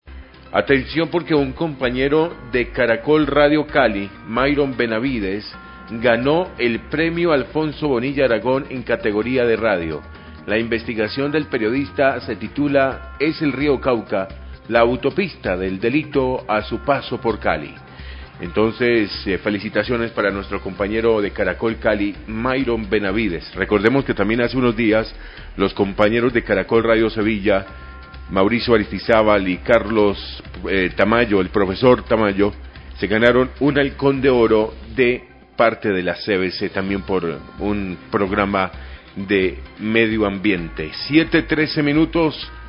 Locutos del informativo felicita a sus compañeros de Caracol Radio Cali que ganaron en su categoria en el premio Alfonso Bonilla Aragón y recuerda que periodistas de esa emisora en sevilla ganaron premio en el concurso Halcón de Oro que reliza la CVC.